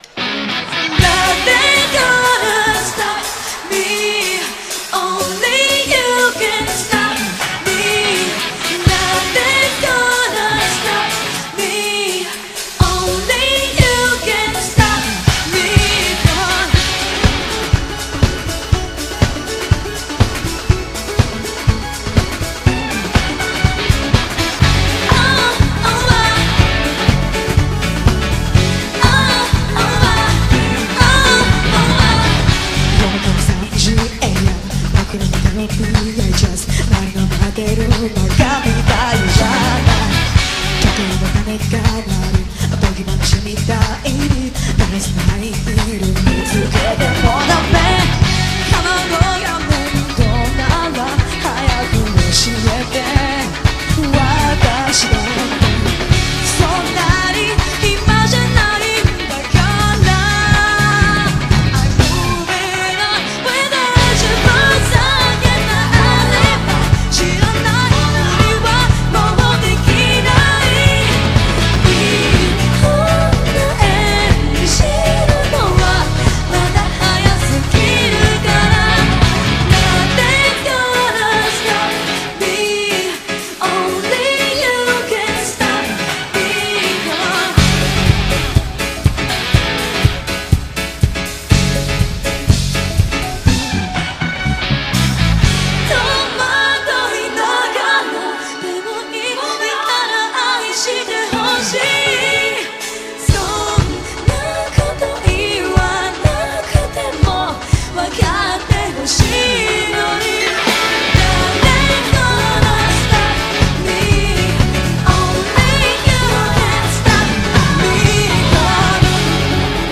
BPM122-124
Quite an exciting rendition of this song.